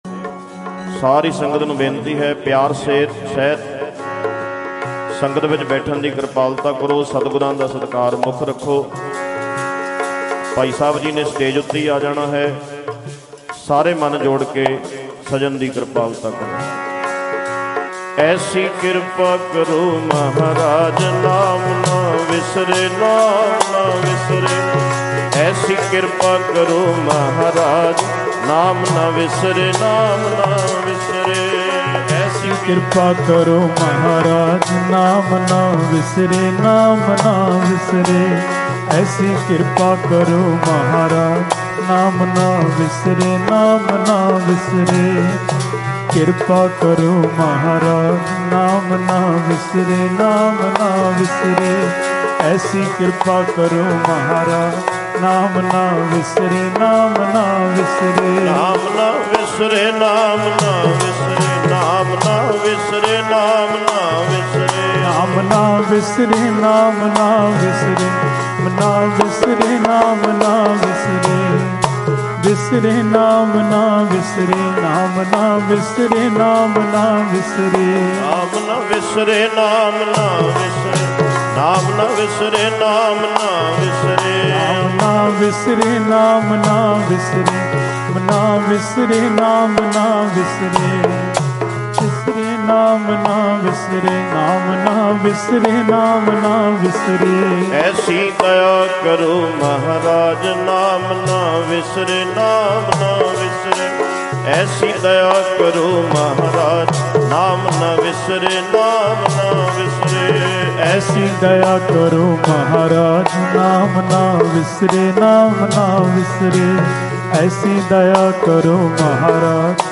G Parmeshar Dwar Sahib Samagam 7 7 2015 | Dhadrianwale
Mp3 Diwan Audio by Bhai Ranjit Singh Ji Dhadrianwale at Parmeshardwar